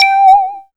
1704R SYNTON.wav